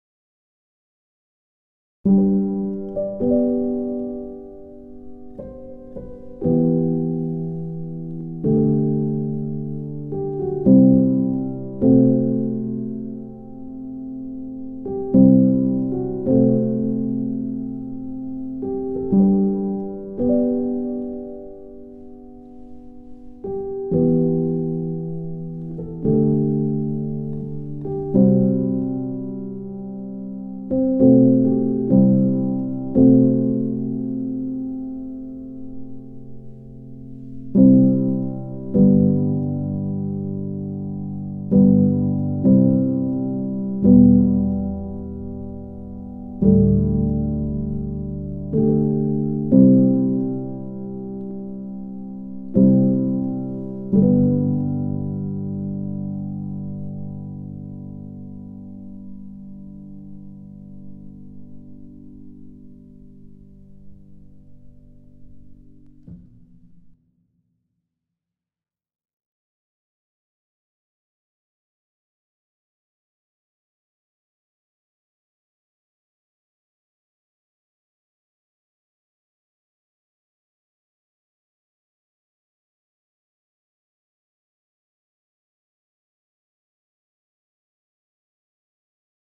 Quick felt piano demo
Pianobook JM felt piano in Audio Layer +ProR.